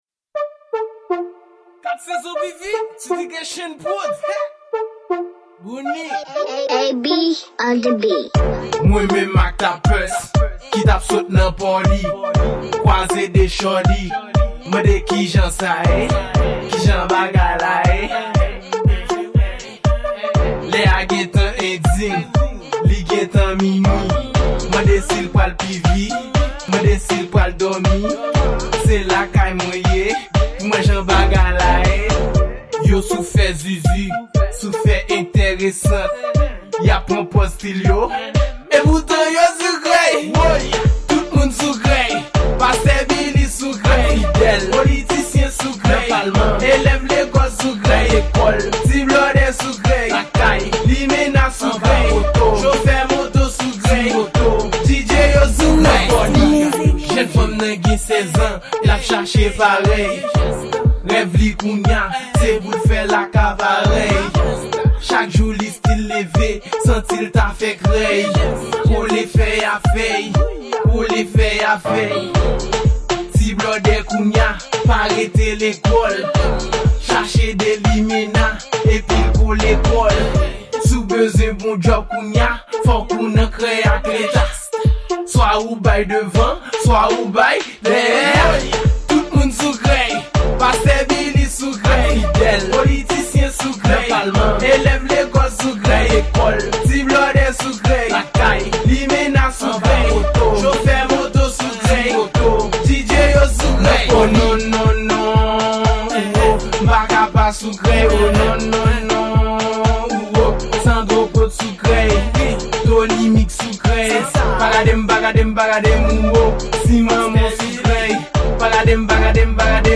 Genre: Raboday